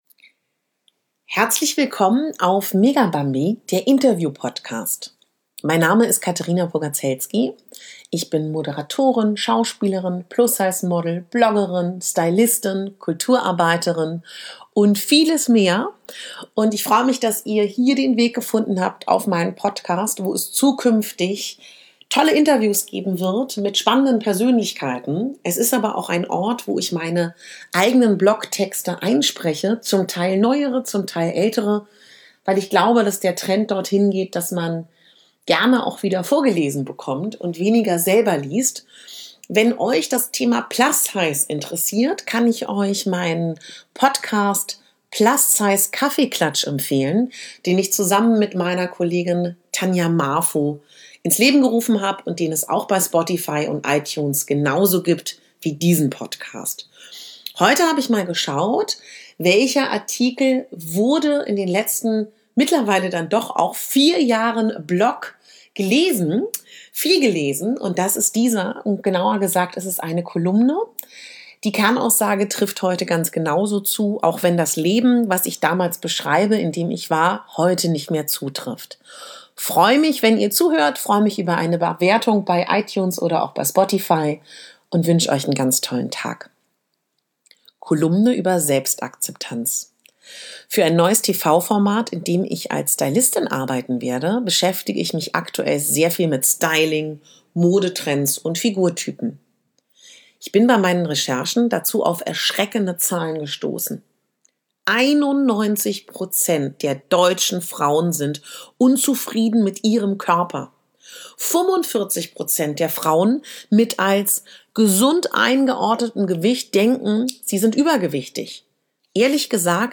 Heute spreche ich Euch eine meine meist gelesenen Kolumnen ein der letzten 4 Jahre.